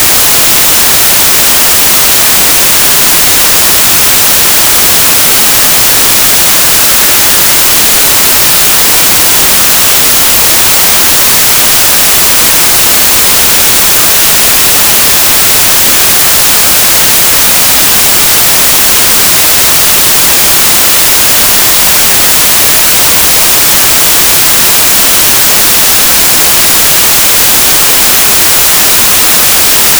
whitenoise.wav